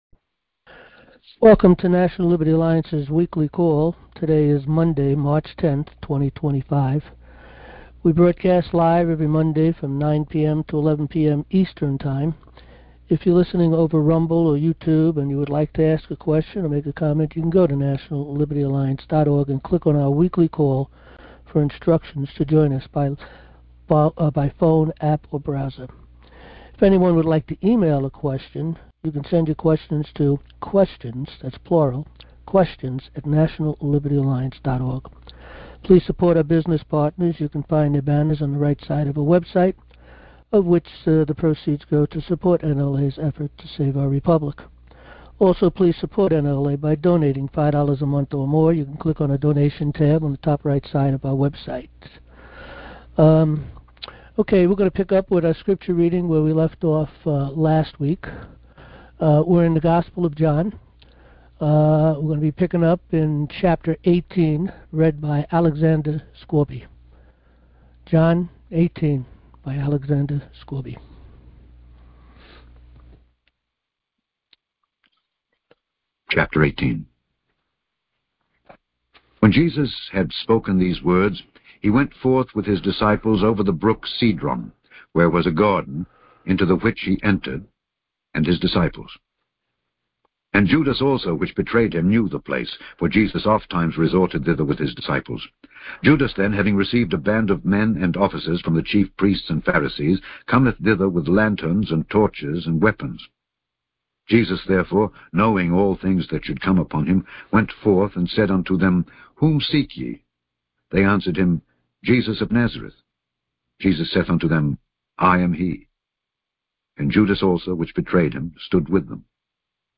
Monday Night Recordings